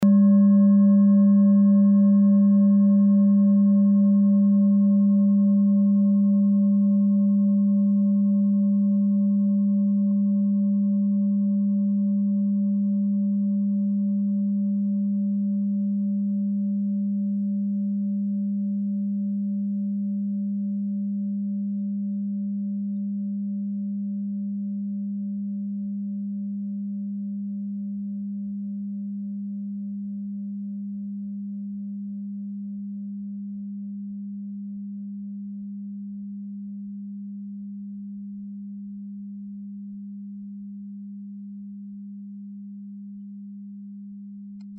Klangschalen-Typ: Bengalen
Klangschale Nr.5
(Aufgenommen mit dem Filzklöppel/Gummischlegel)
klangschale-set-2-5.mp3